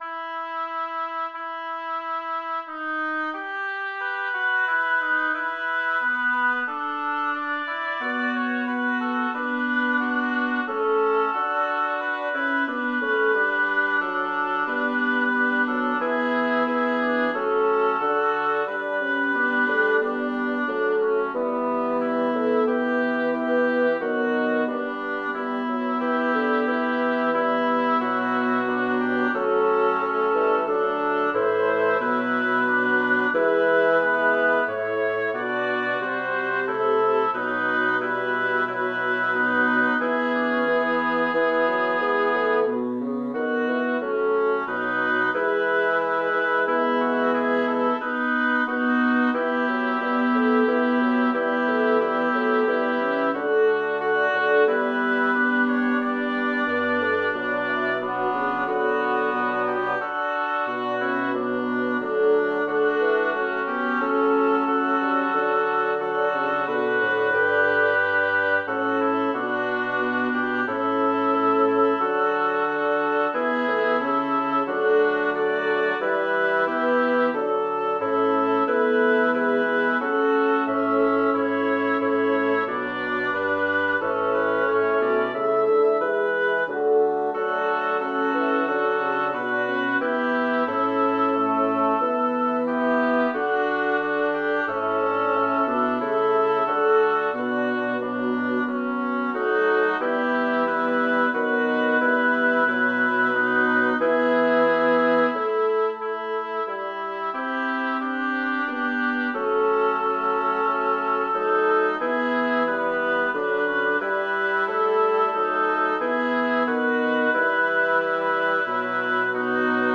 Title: Si ergo offers munus tuum Composer: Sebastian Homo Lyricist: Number of voices: 5vv Voicing: SATTB Genre: Sacred, Motet
Language: Latin Instruments: A cappella